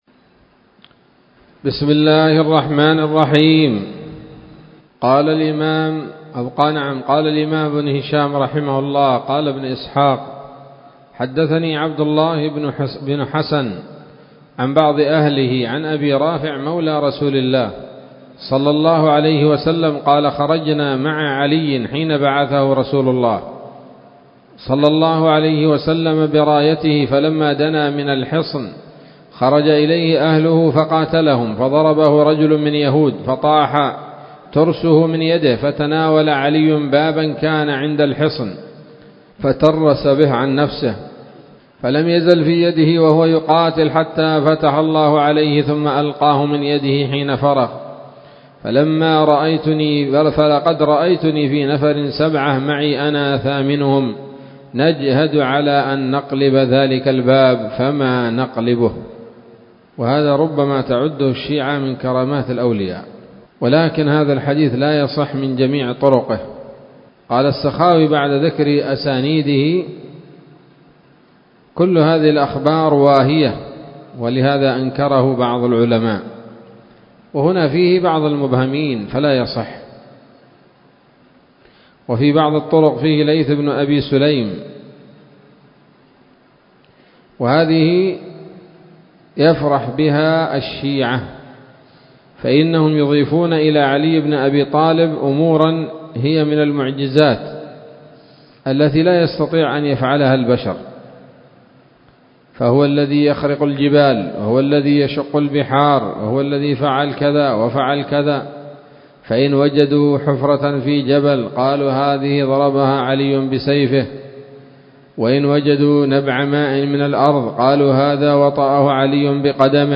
الدرس الثاني والأربعون بعد المائتين من التعليق على كتاب السيرة النبوية لابن هشام